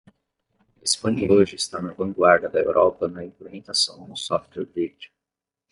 Pronounced as (IPA) /vɐ̃ˈɡwaʁ.dɐ/